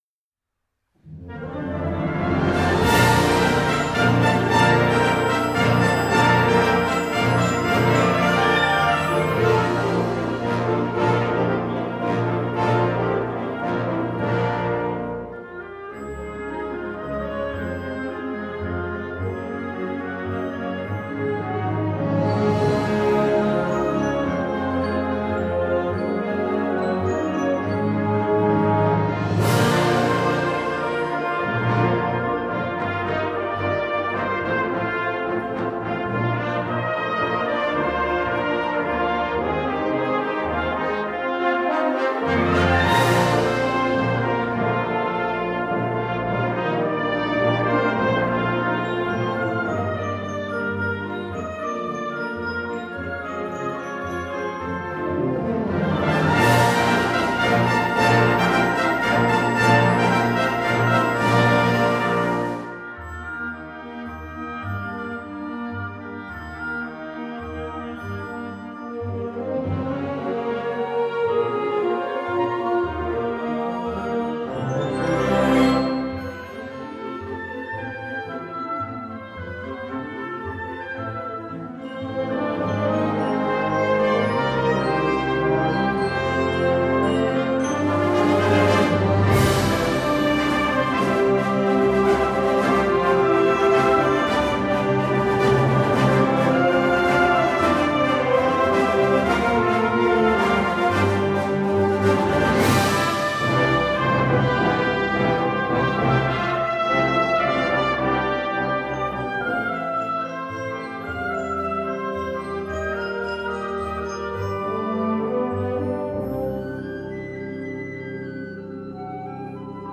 Partitions pour orchestre d'harmonie.